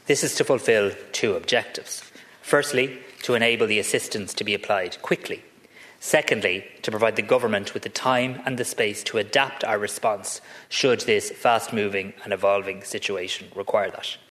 The Tanaiste has told the Dail new energy supports are on the way.
Responding, the Tanaiste did not specify what the measures would be, but said they will be in “areas in which there is the most acute pressure & challenge”.